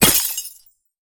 ice_spell_impact_shatter_08.wav